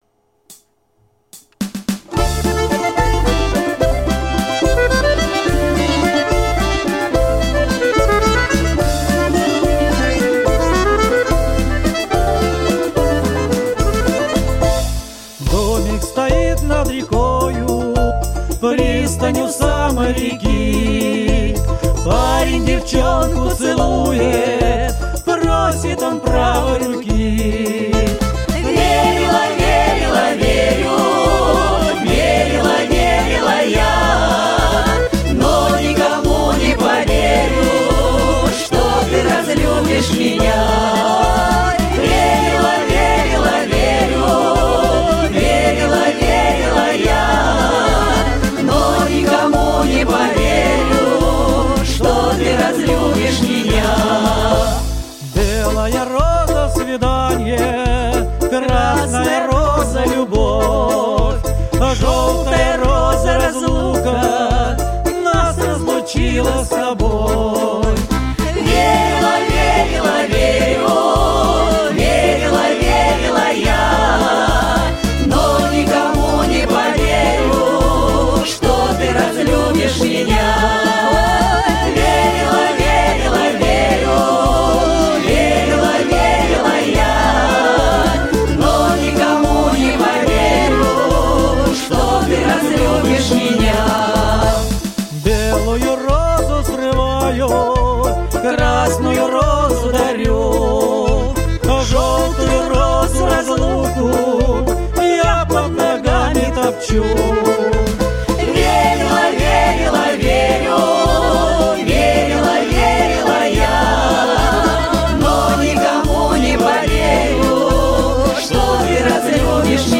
Коллектив почти год трудился в студии звукозаписи